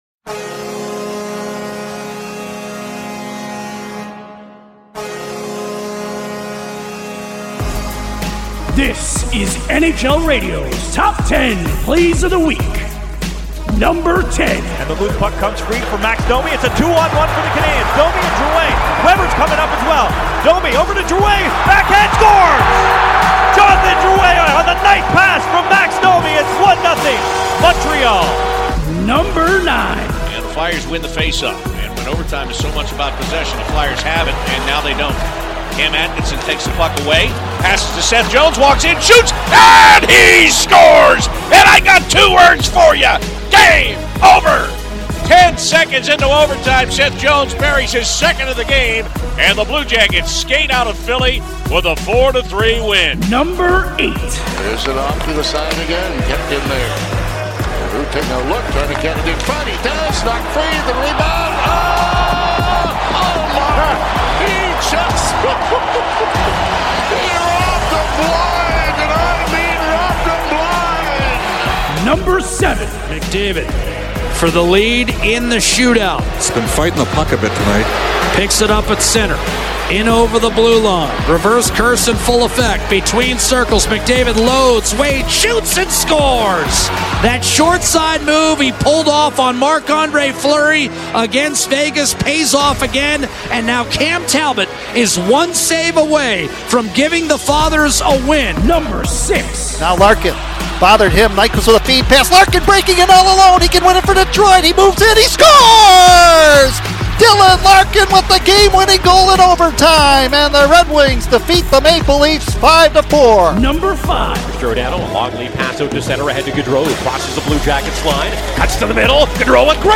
Week of Monday, December 3rd: Your source for the top radio calls, plays and highlights from around the National Hockey League!